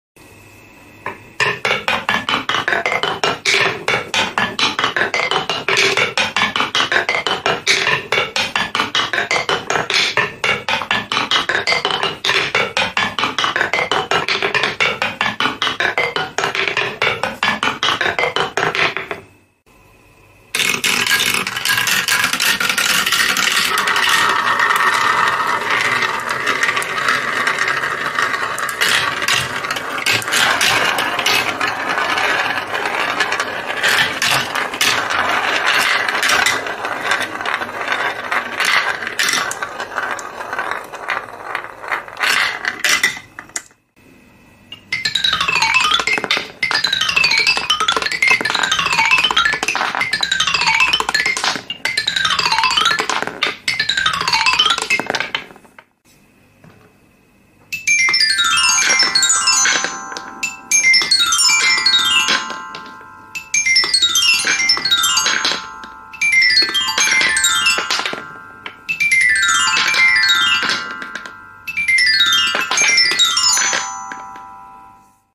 ASMR Marble run (Glockenspiel, Xylophone